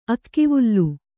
♪ akkivullu